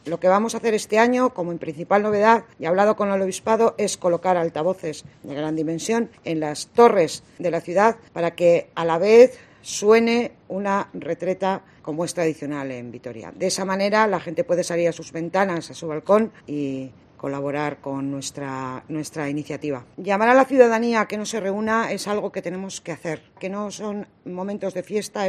Ana del Val, dipuatada de Cultura de Alava